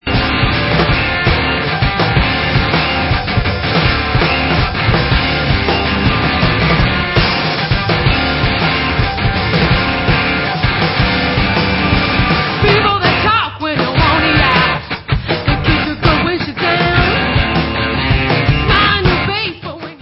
rocking-soul side-project
sledovat novinky v kategorii Rock